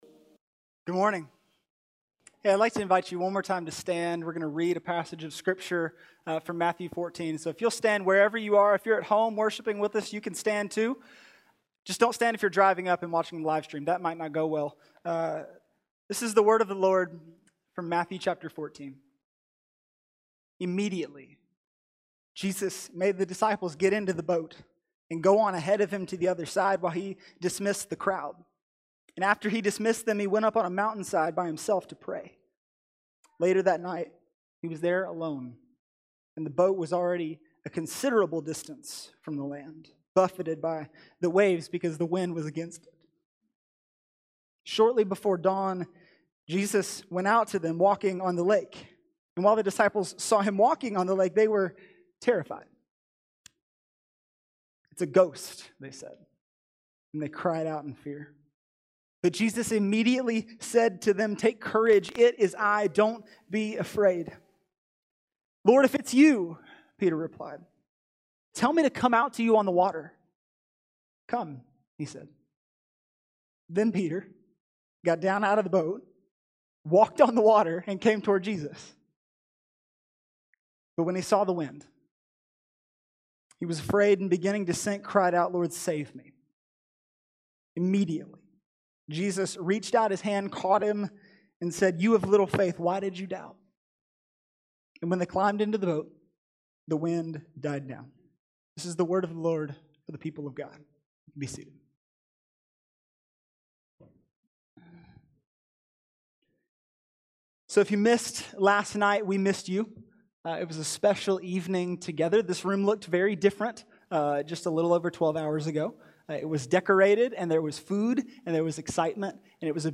Graduation Sunday 2021